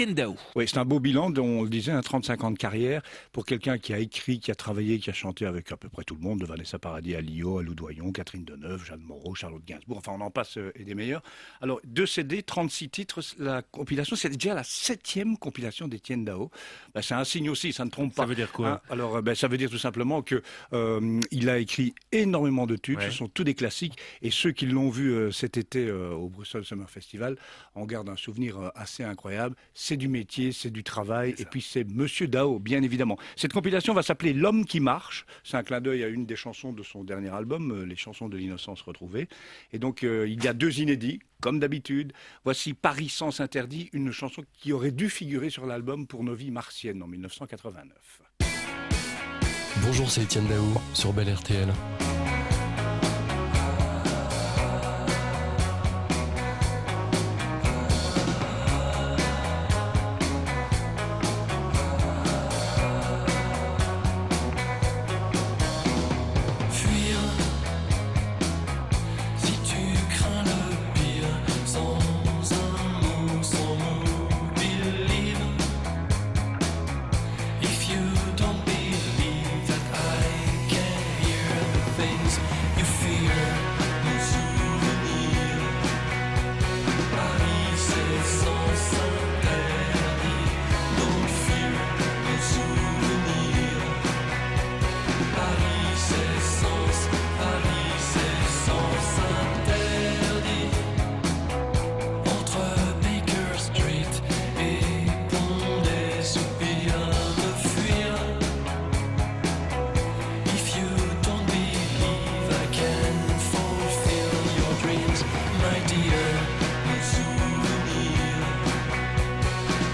chronique musicale) Bel RTL